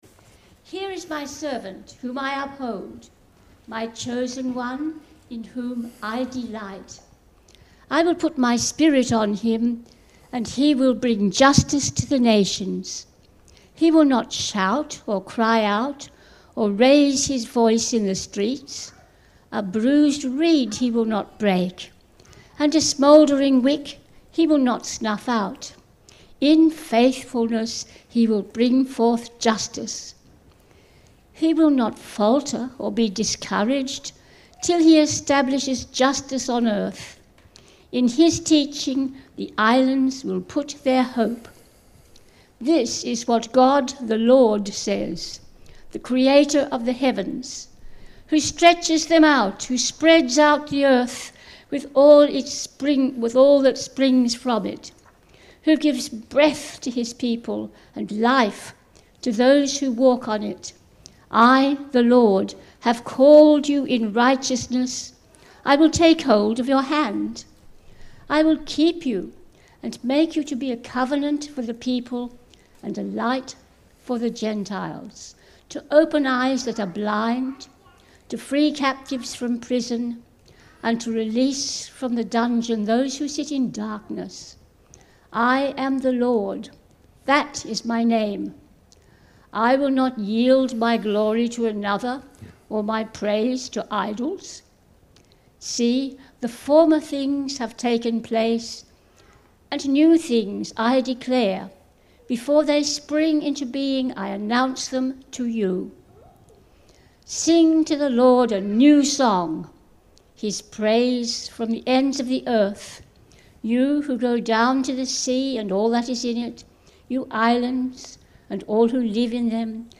Talk Outline Spiritual Information Led Astray Speaking in the Spirit No one does Bible Passages 1 Corinthians 12:1-3 Topics 3.1.15. World Missions, 3.1.14. Witness Series Individual Talks Contexts Guest Services Date 5 Apr 2025 Type Audio , Video Share & More Download Audio